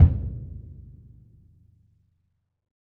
BDrumNewhit_v4_rr2_Sum.wav